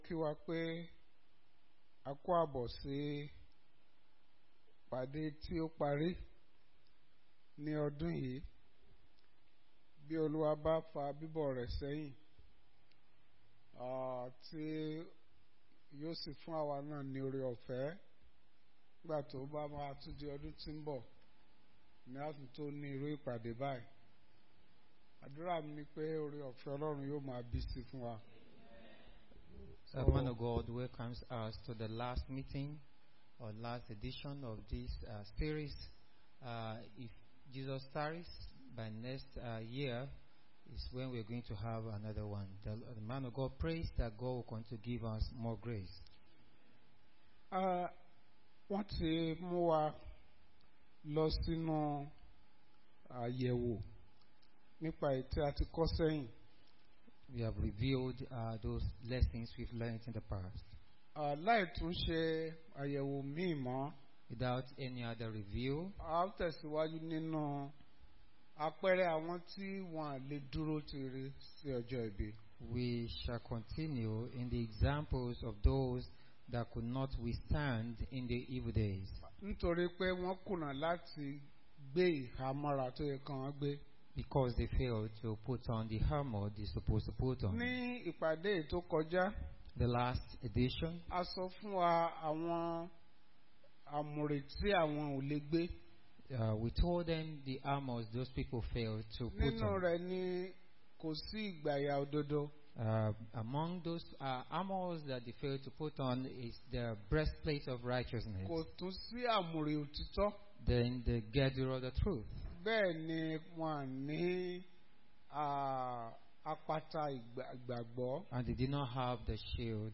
Bible Class Passage: 2 Timothy 4:10